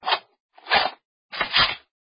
eqp_awm_reload.mp3